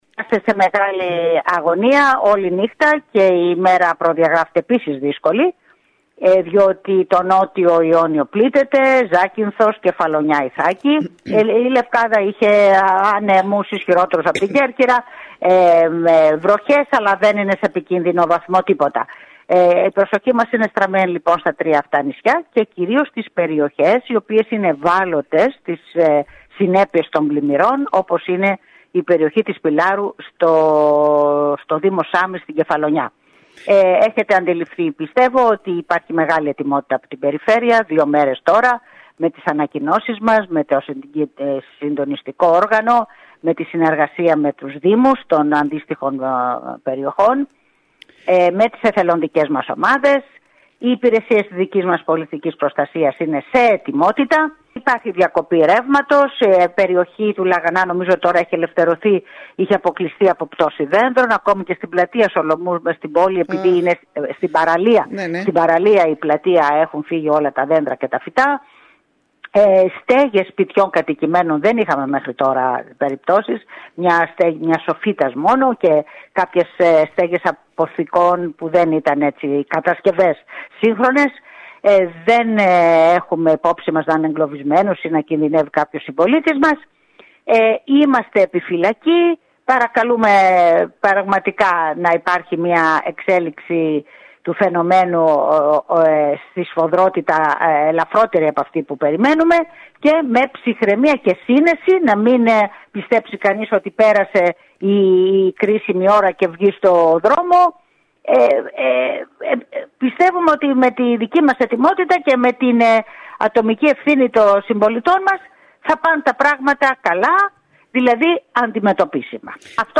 Μιλώντας σήμερα το πρωί στην ΕΡΤ Κέρκυρας η περιφερειάρχης Ιονίων Νήσων Ρόδη Κράτσα υπογράμμισε πως ο μηχανισμός της πολιτικής προστασίας βρίσκεται σε ετοιμότητα και κατάφερε να αντιμετωπίσει ικανοποιητικά τα όποια προβλήματα παρουσιάστηκαν ενώ εξέφρασε την αγωνία της και για τις επόμενες ώρες που αναμένεται να εκδηλωθούν ισχυρές βροχοπτώσεις και  υπάρχει κίνδυνος να σημειωθούν έντονα πλημμυρικά φαινόμενα  στα νησιά του νοτίου Ιονίου.